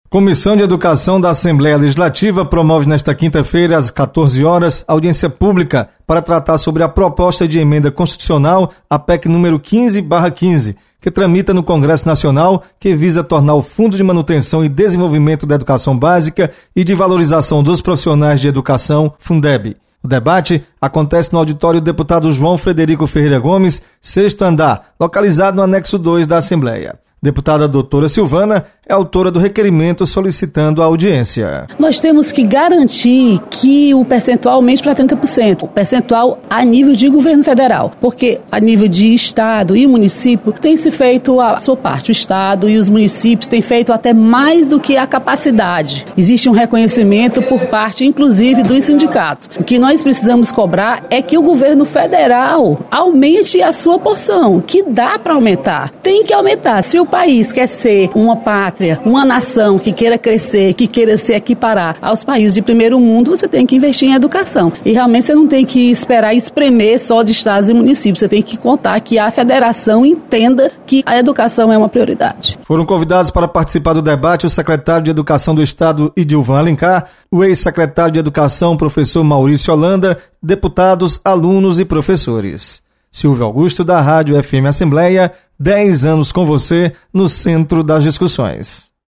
Você está aqui: Início Comunicação Rádio FM Assembleia Notícias Comissões